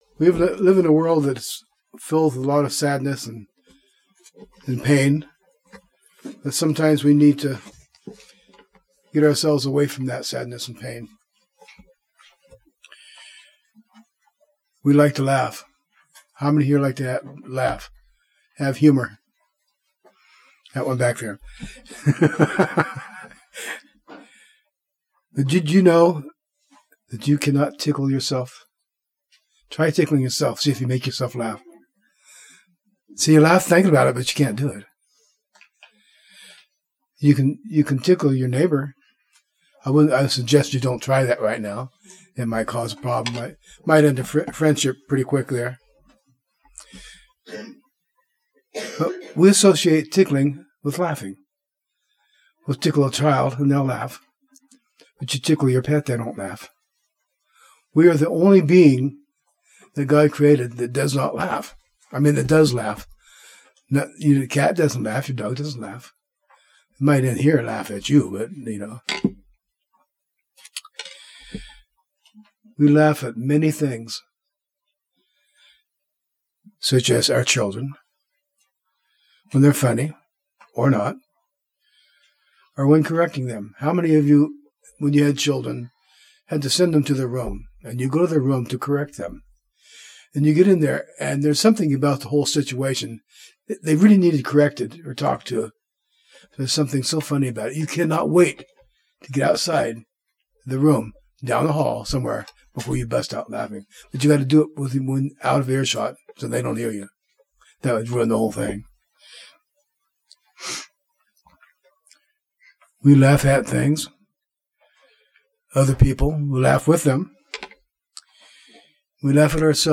Given in Northwest Arkansas